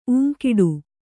♪ ūŋkiḍu